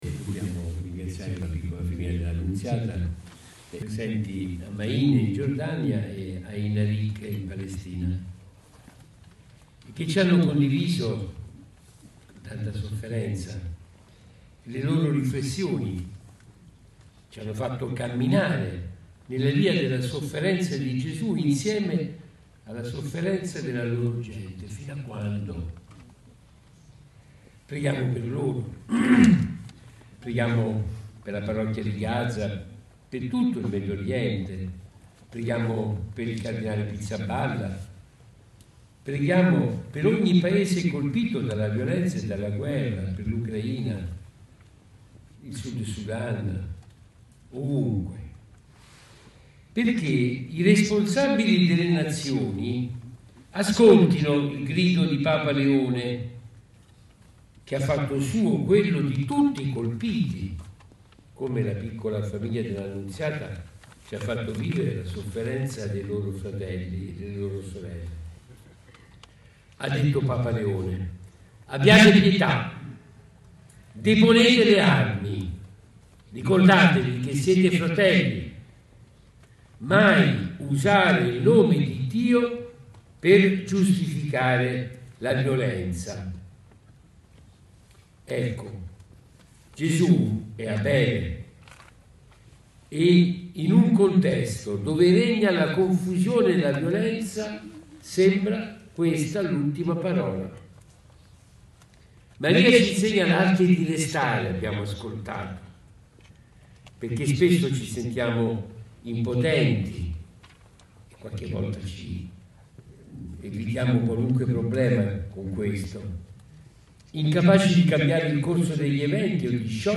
La Via Crucis lungo il Colle dell’Osservanza
Qui l’audio della riflessione dell’Arcivescovo